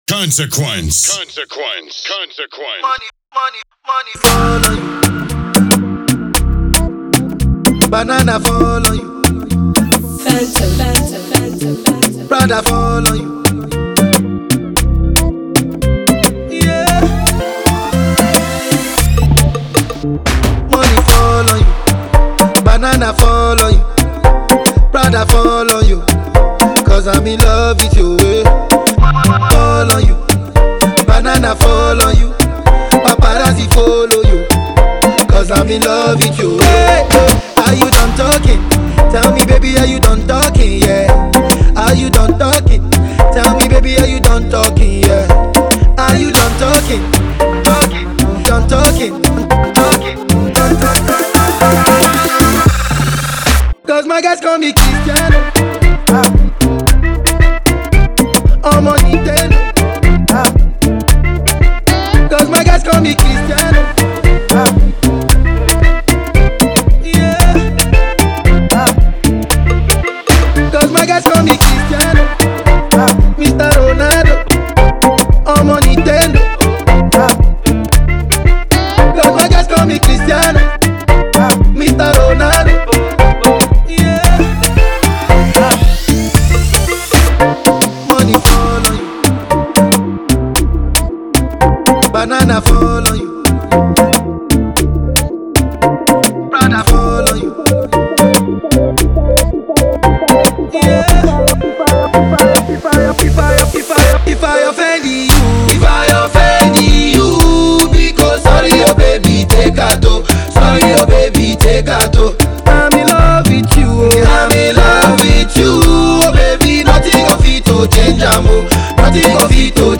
AFRO EDM REFIX